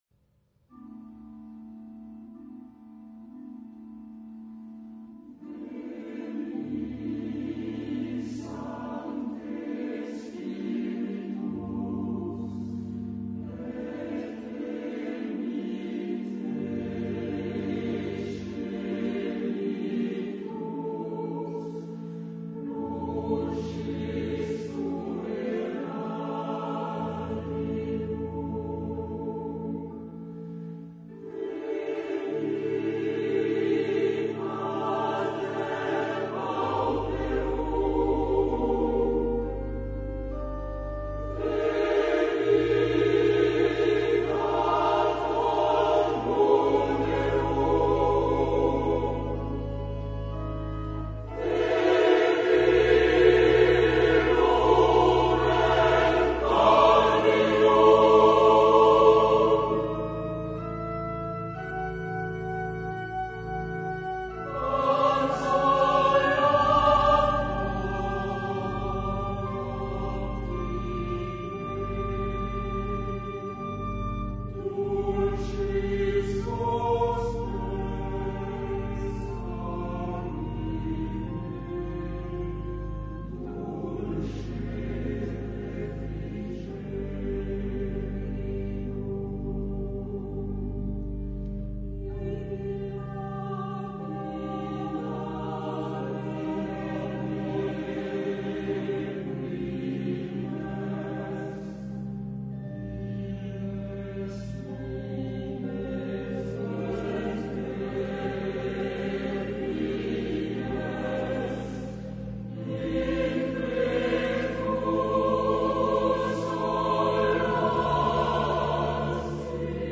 note: afin de ménager la bande passante, les fichiers en écoute immédiate sont encodés en mono, 22 KHz, 32 Kbps, ... ce qui signifie que leur qualité n'est pas "excellentissime"!